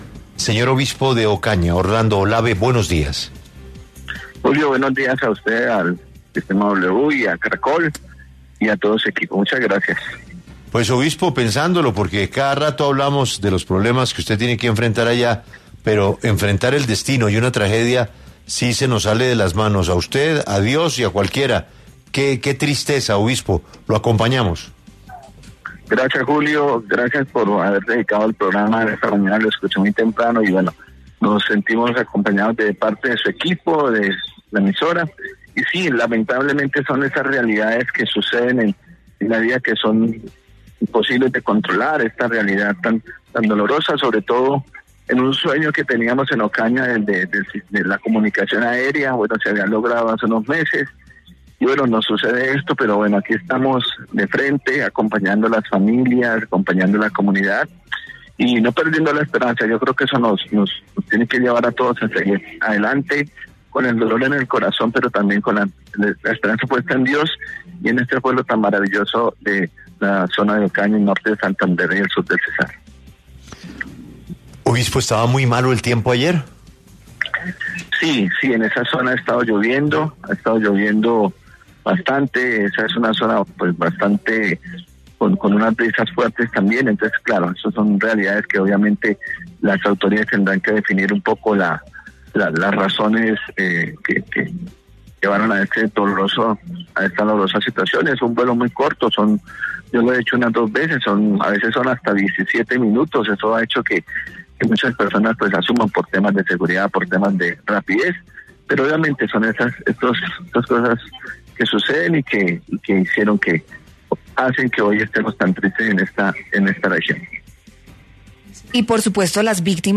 En 6AMW estuvo el Obispo Orlando Olave, quien habló sobre el trabajo que venía realizando Diógenes Quintero en la región:
En 6AMW de Julio Sánchez Cristo, estuvo el Obispo Orlando Olave, quien lamentó el accidente aéreo ocurrido el pasado miércoles 28 de enero en la vía Ocaña-Cúcuta.